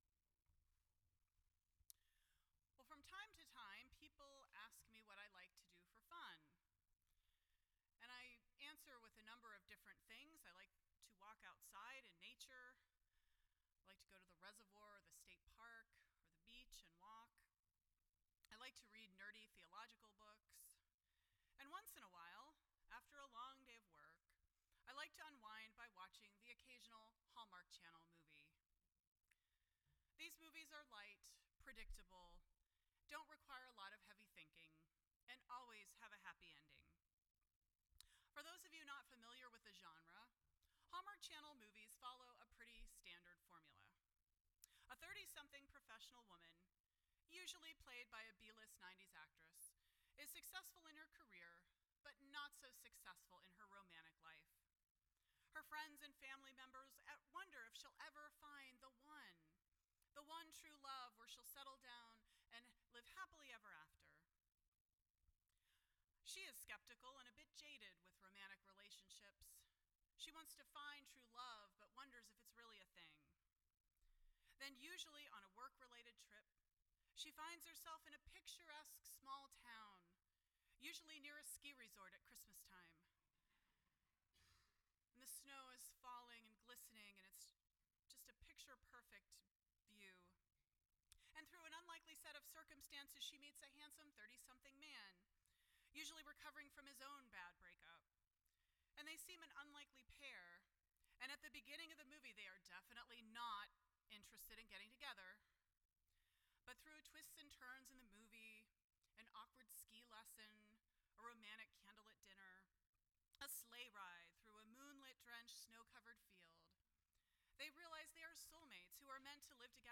None Service Type: Sunday Morning %todo_render% Share This Story